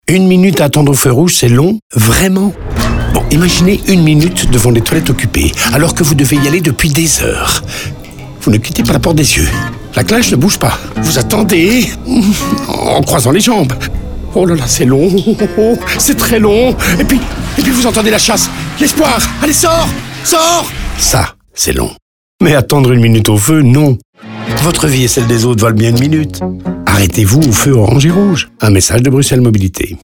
Ces 3 sujets sont déclinés en radio, OOH et en social.